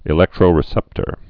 (ĭ-lĕktrō-rĭ-sĕptər)